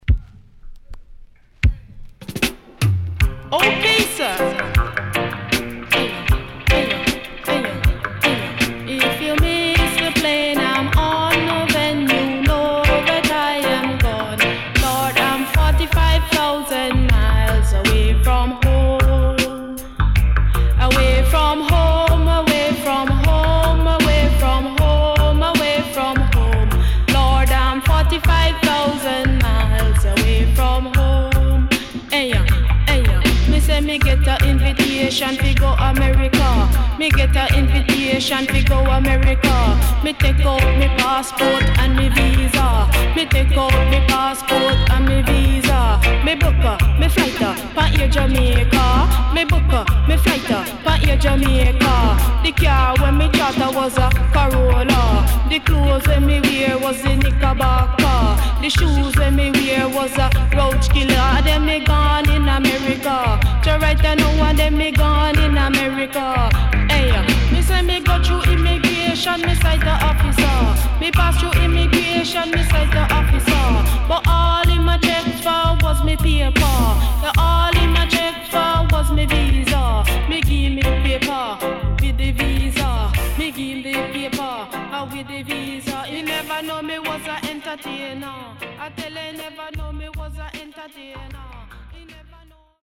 HOME > REISSUE [DANCEHALL]